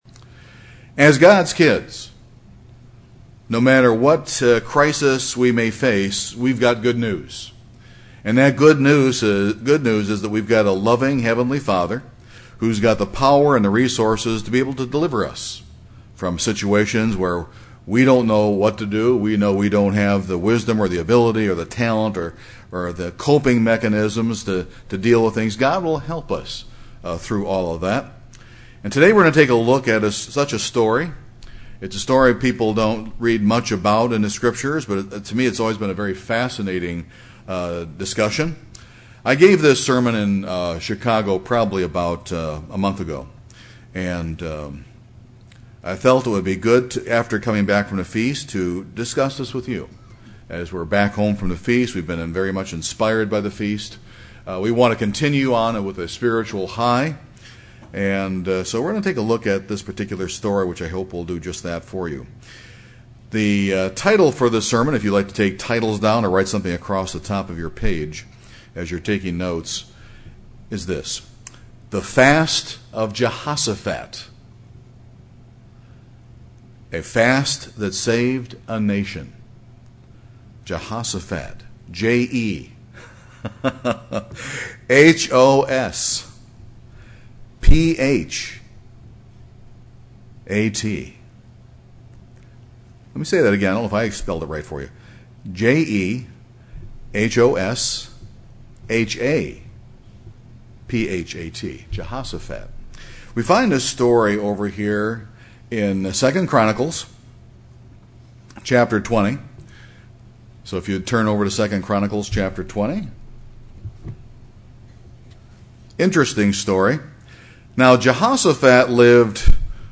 This sermon examines that account and gives food for thought that we have the loving heavenly Father who has the resources to deliver us and the ability for us is thus enhanced through proper fasting.